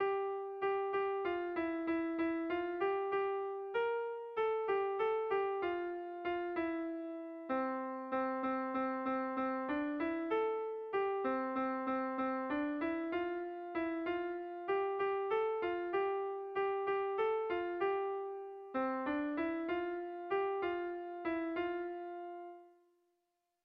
Gabonetakoa
Urte-zahar gaueko kopla, DA BART BELENEN gehigarriarekin.
Lauko handia (hg) / Bi puntuko handia (ip)